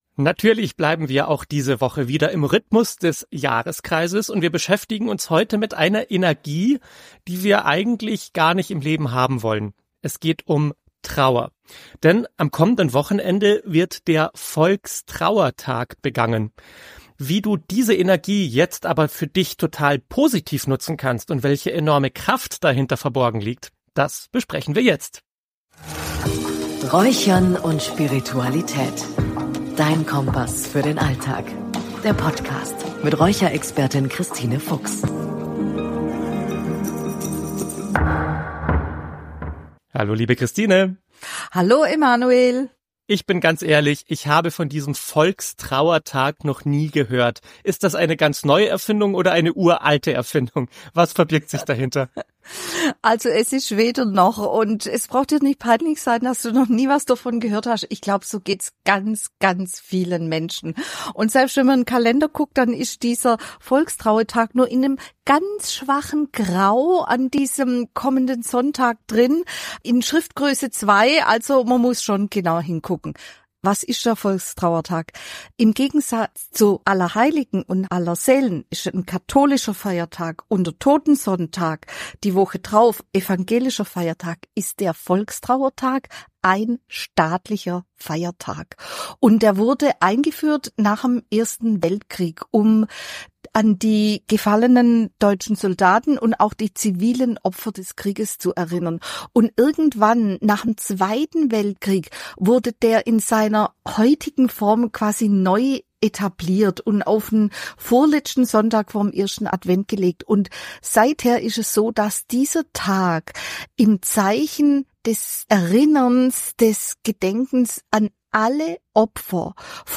Diese Meditation wird dich erden und dir neue Kraft geben.